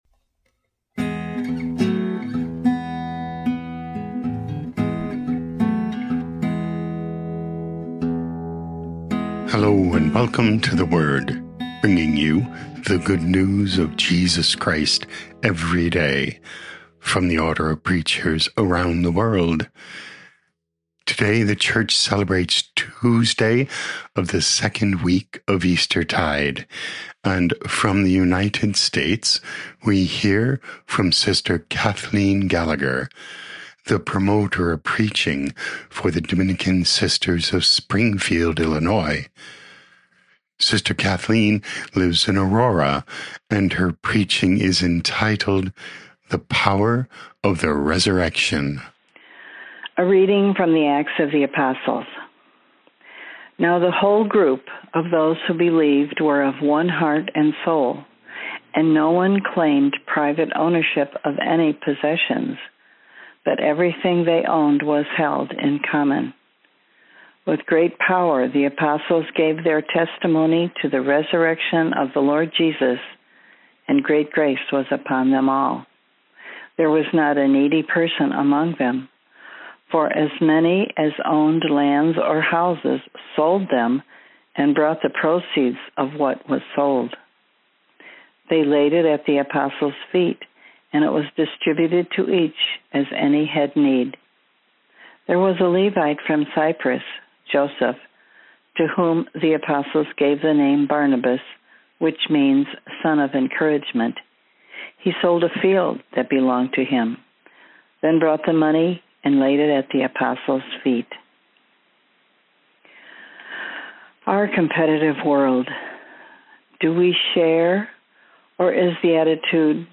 OP Preaching